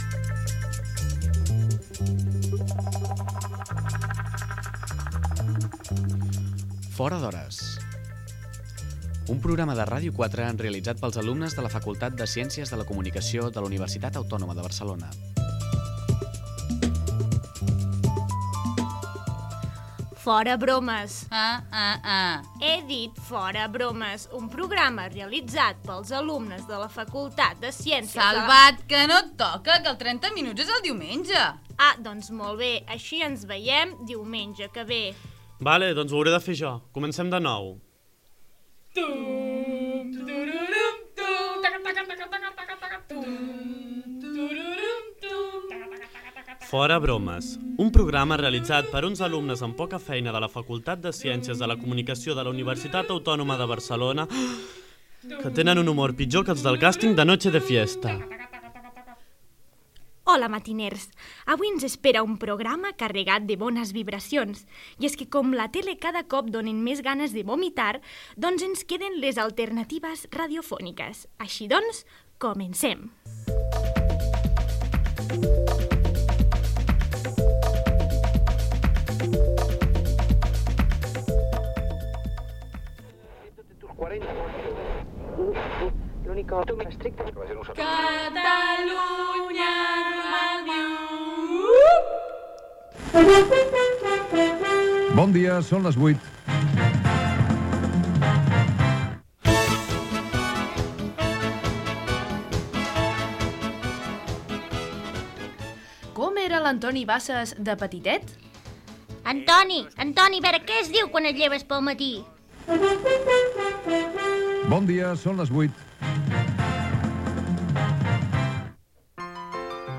Careta del programa, presentació de l'espai dedicat a les imitacions de professionals de la ràdio i peces humorítiques sobre els seus programes
Entreteniment
enregistrat als seus estudis del campus de Bellaterra